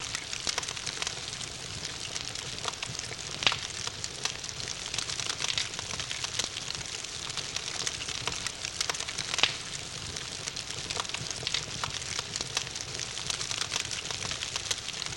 Campfire With Night Ambience, Owl Hooting